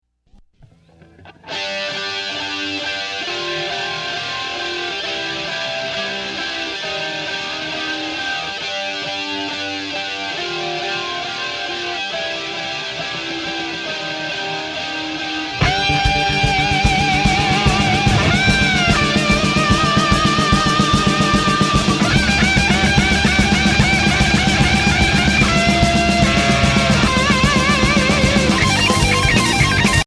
Composer: Rock
Voicing: Guitar Tab